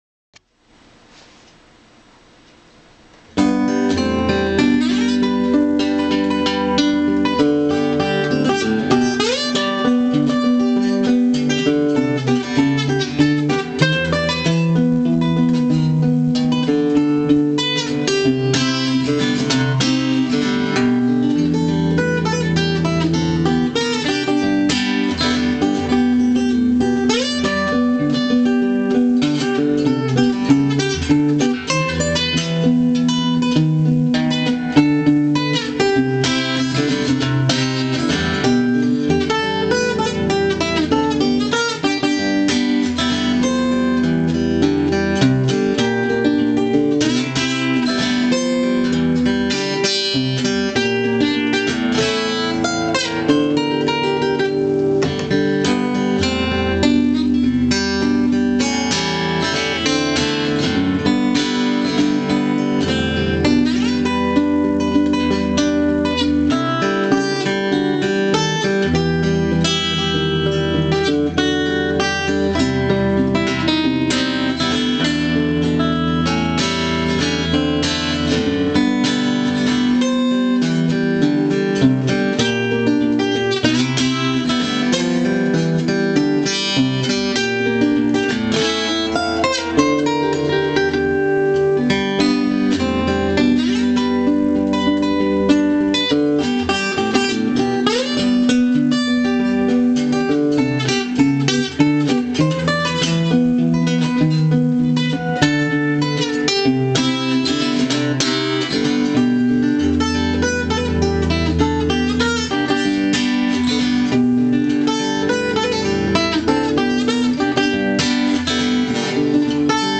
a young solo version